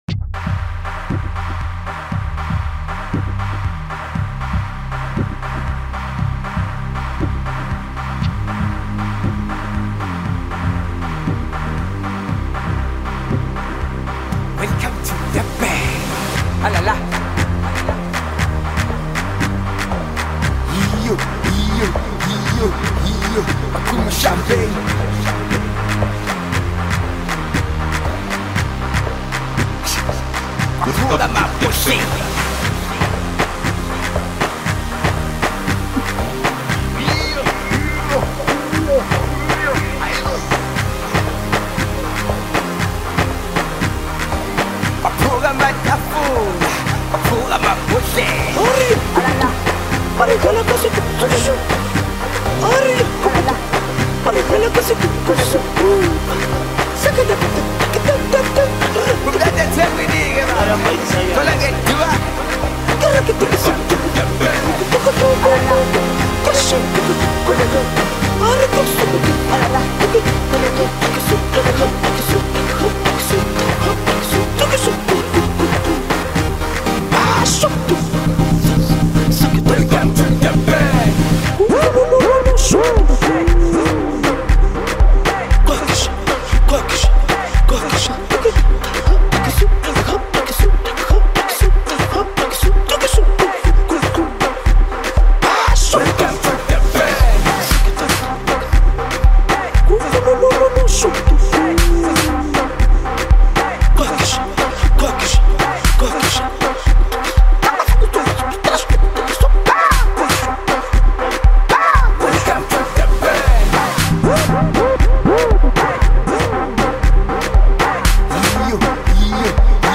Home » Amapiano » DJ Mix » Hip Hop
South African singer